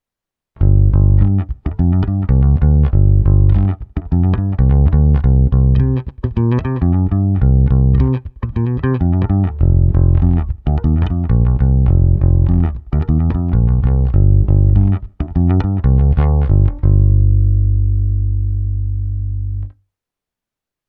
Zvukově je to klasický Precision, i s hlazenkami hraje naprosto parádně, a oproti mnoha jiným Precisionům má tenhle opravdu pevné, vrčivé Éčko.
Stejné kolečko jako ukázka 2, ale už s kompresorem, ekvalizací a simulací aparátu:
Ukázka 3 - simulace XLR výstupu zesilovače Ampeg SVT (tedy bez vlivu reproduktoru)